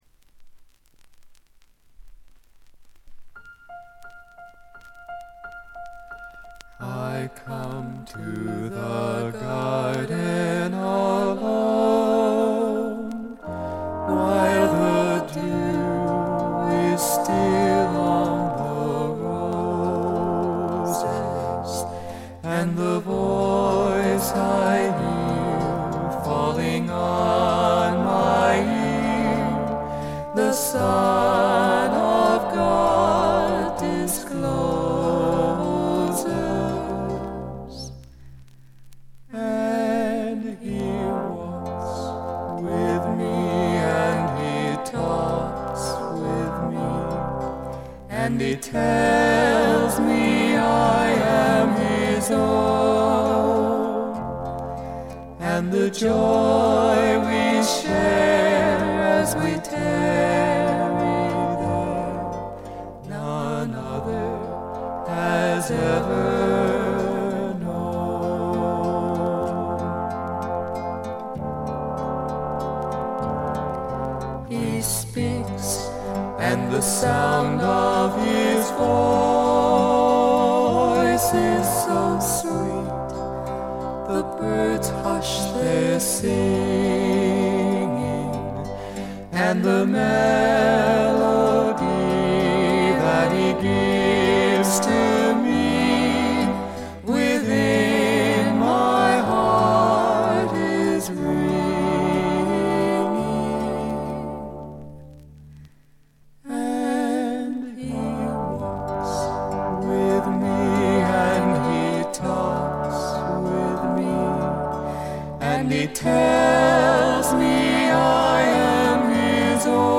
プレスのためかバックグラウンドノイズ、チリプチが出ます。
知る人ぞ知る自主制作ポップ・フォークの快作です。
試聴曲は現品からの取り込み音源です。